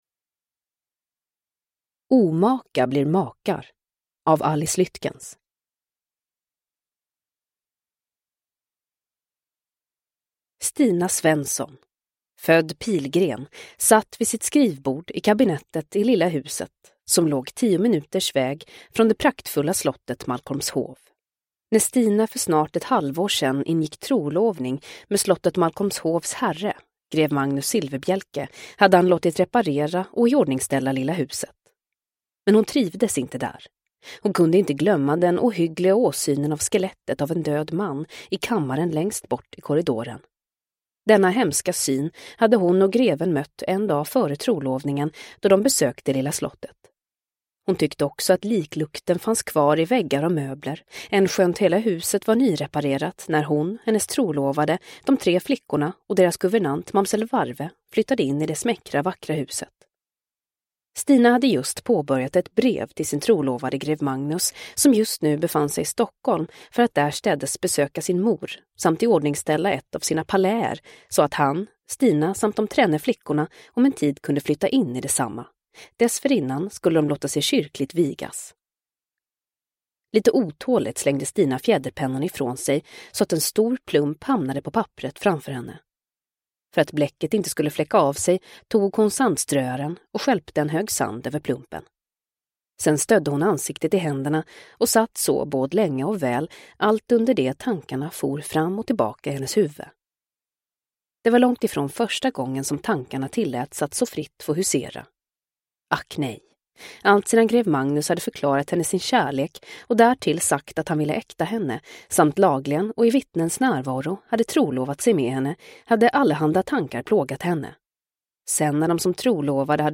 Omaka blir makar – Ljudbok – Laddas ner